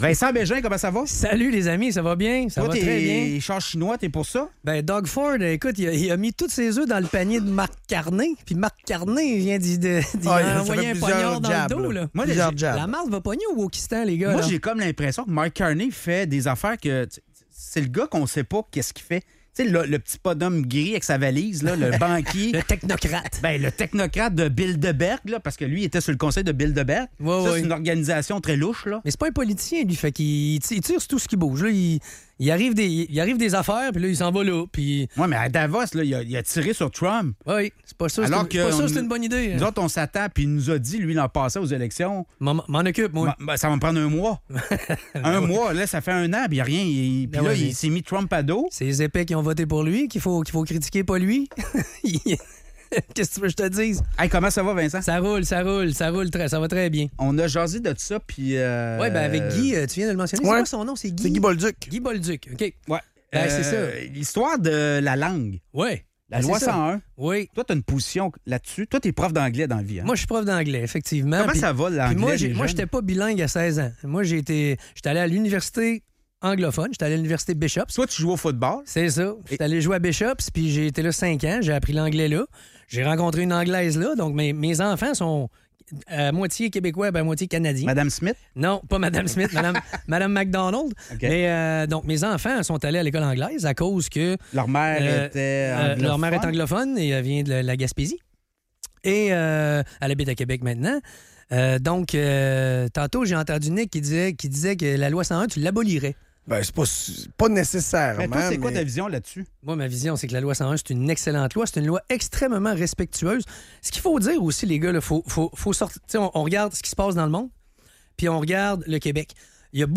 La discussion met en lumière l'importance de la loi 101 pour la protection et la promotion du français au Québec, tout en respectant la communauté anglophone. Les intervenants débattent des enjeux d'accès à l'éducation anglophone pour les francophones et des implications culturelles de subventionner des écoles anglophones.